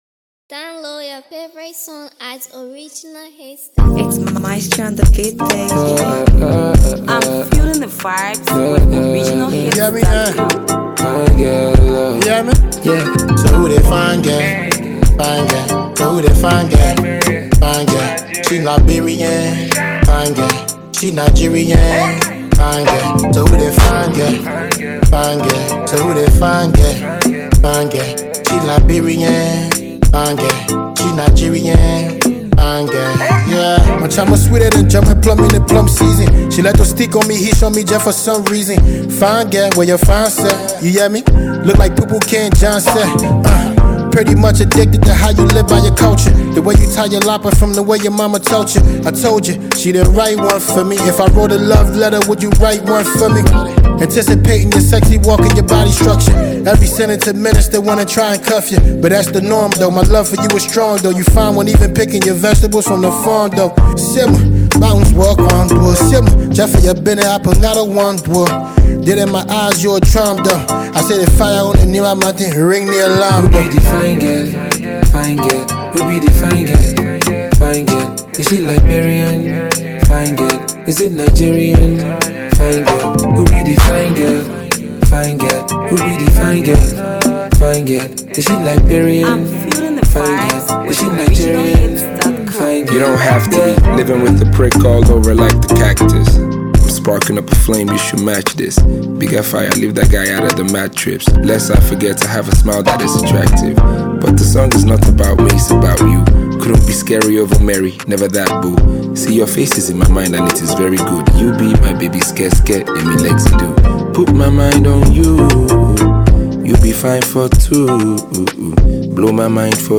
Liberian sensational trap guru